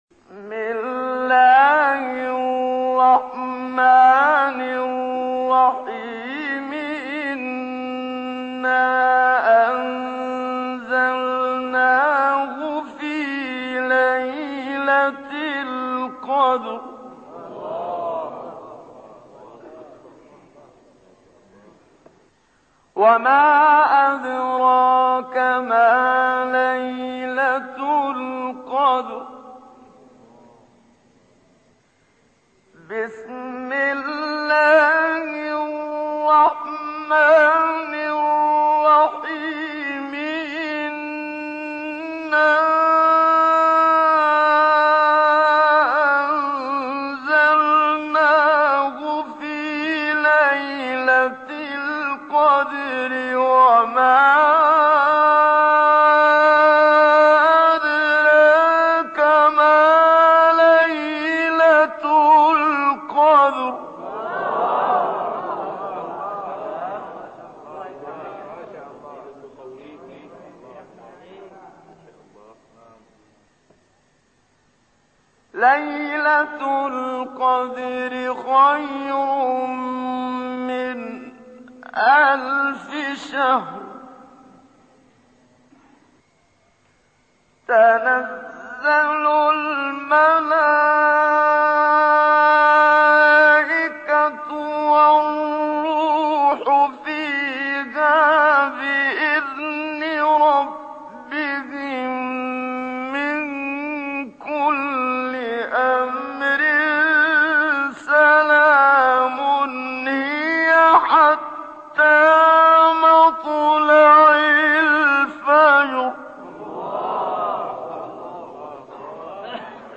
تلاوت سوره‌ قدر با صوت قاریان مصری